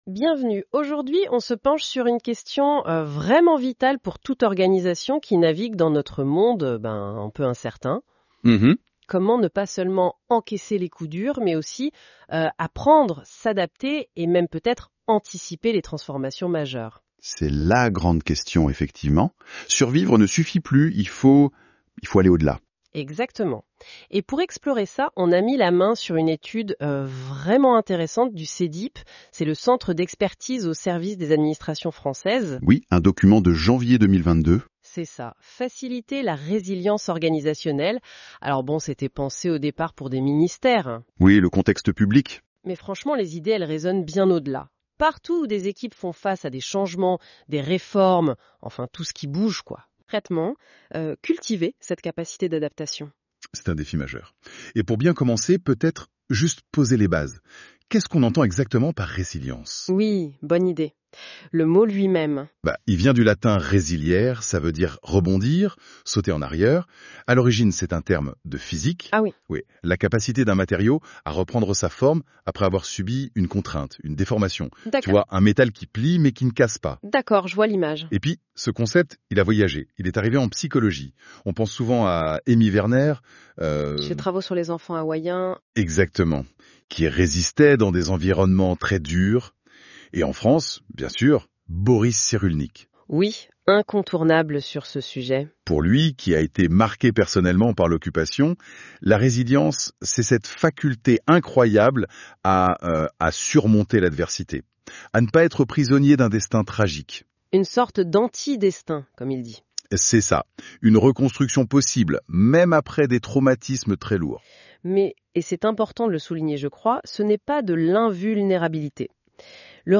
Entretien : La résilience Organisationnelle
Podcast généré à l'aide de l'IA NotebookLM